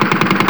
mchgun9.wav